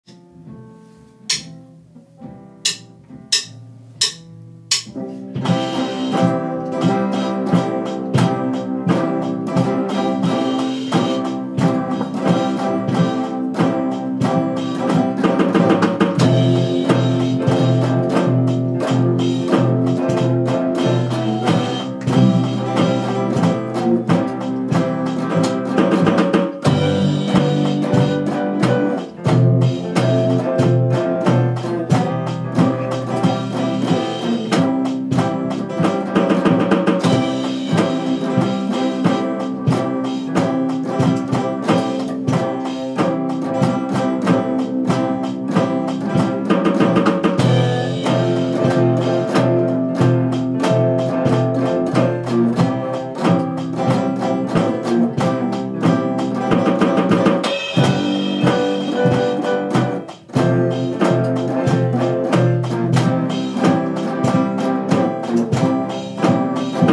Polishing Band recording